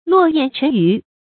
落雁沉魚 注音： ㄌㄨㄛˋ ㄧㄢˋ ㄔㄣˊ ㄧㄩˊ 讀音讀法： 意思解釋： 雁見了飛落地面，魚見了潛入水底。